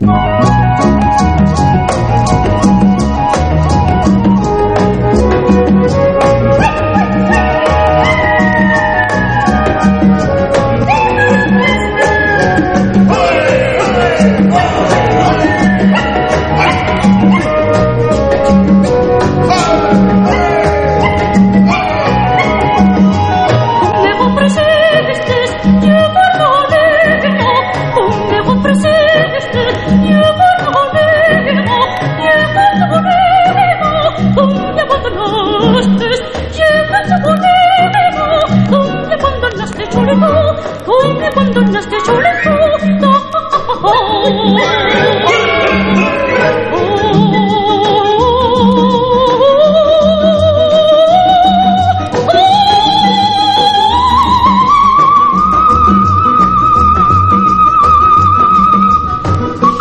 BREAKBEATS/HOUSE / EXOTICA / ELECTRO
時代にのっとったヒップなサウンド、ルックスこそ大分オバチャンになりましたが、美しいソプラノ・ヴォイスは健在です！